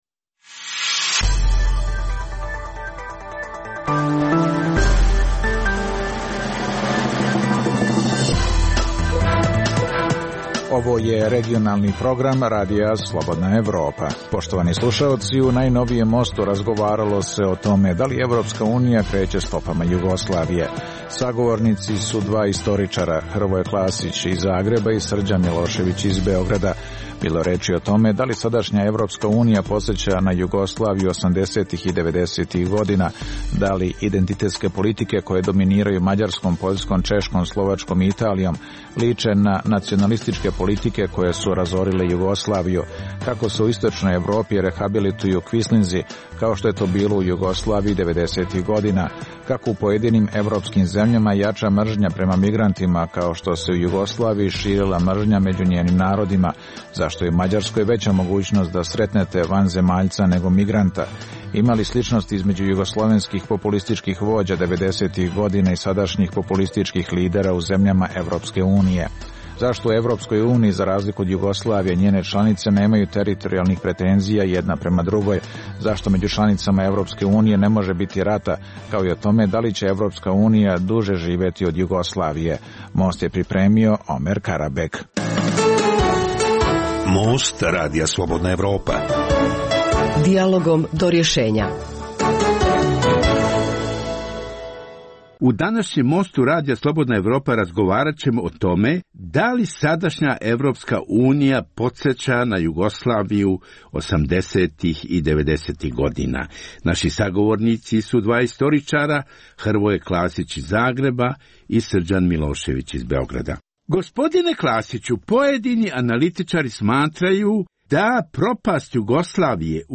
u kojem ugledni sagovornici iz regiona razmatraju aktuelne teme. Drugi dio emisije čini program "Pred licem pravde" o suđenjima za ratne zločine na prostoru bivše Jugoslavije.